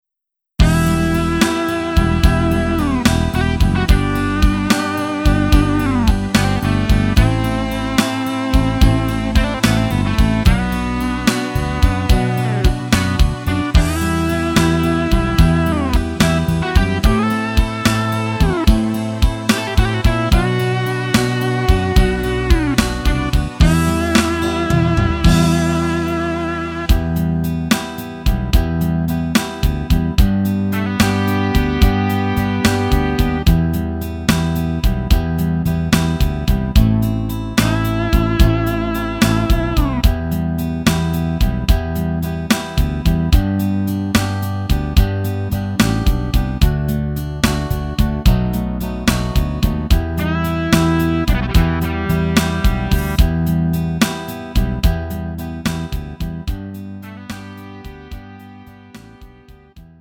음정 원키 3:53
장르 구분 Lite MR